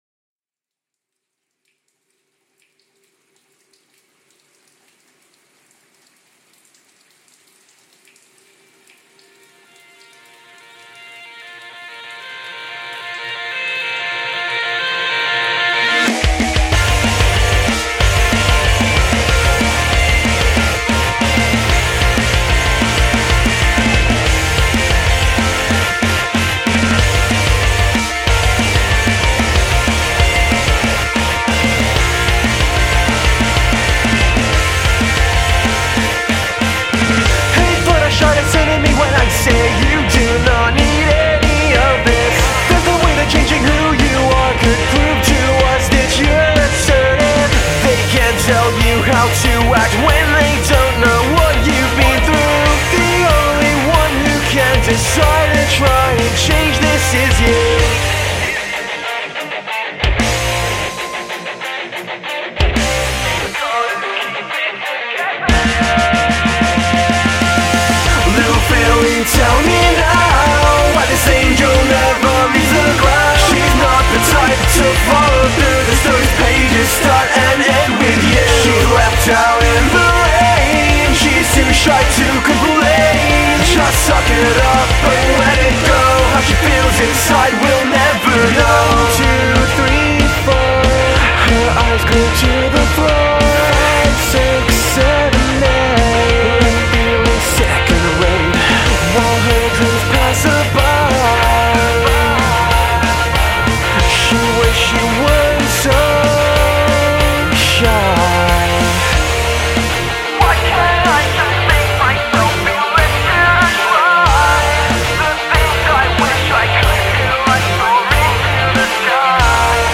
(sick metals and awesome riffage!)
(just getting started, awesome guy and great singer!)